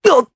Esker-Vox_Damage_jp_02.wav